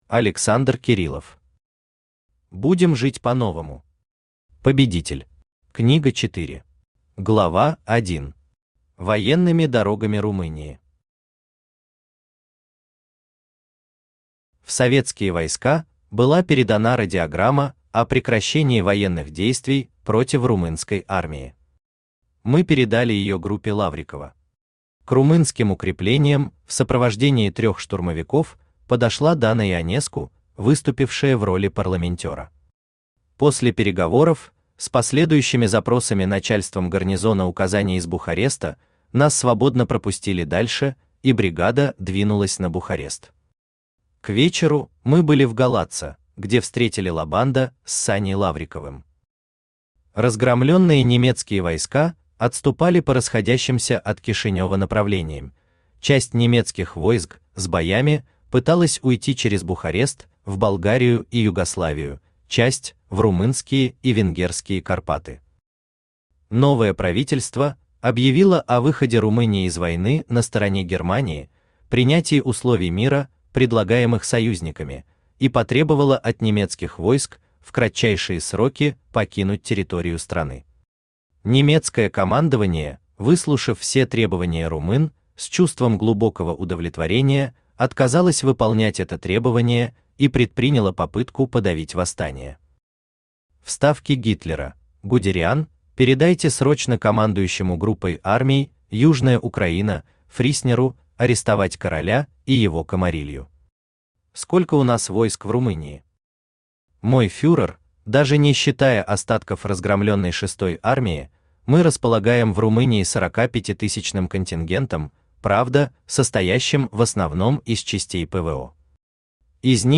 Aудиокнига Будем жить по-новому!
Книга 4 Автор Александр Леонидович Кириллов Читает аудиокнигу Авточтец ЛитРес.